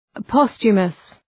Προφορά
{‘pɒstʃəməs}